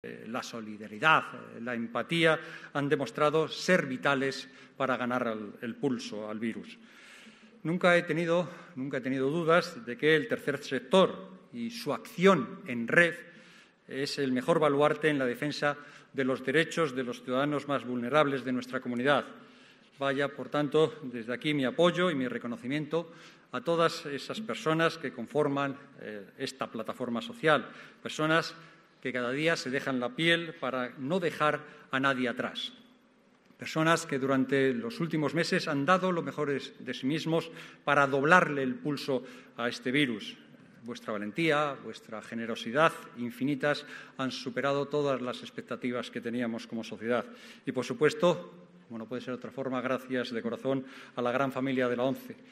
Los Premios Solidarios ONCE Edición Especial 2021Abre Web externa en ventana nueva homenajearon, el pasado 1 de diciembre, la solidaridad y fuerza de la sociedad castellano y leonesa, durante la ceremonia de entrega de galardones que se celebró en el Teatro Zorrilla de Valladolid.